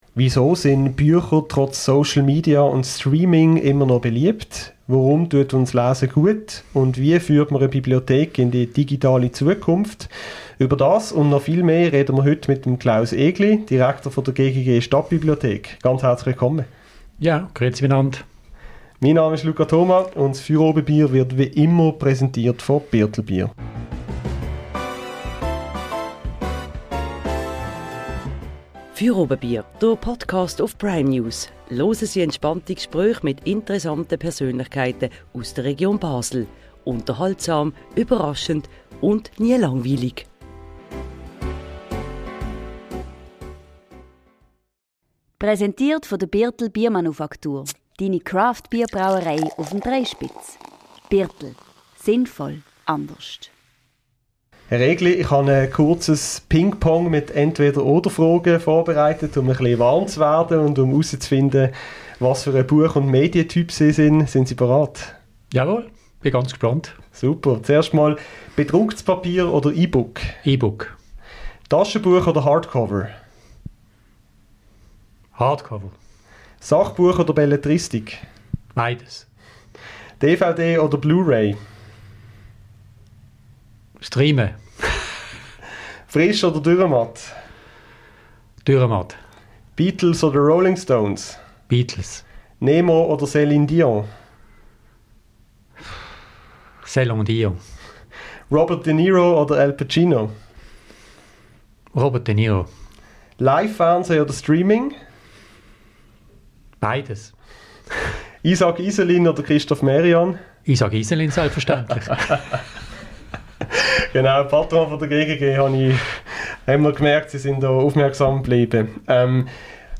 Fürobebier ist die wöchentliche Diskussionssendung des Basler Onlineportals Prime News. Jeweils am Montag diskutieren Mitglieder der Redaktion zusammen mit einem Gast über drei Themen, welche aktuell die Stadt und die Region Basel beschäftigen.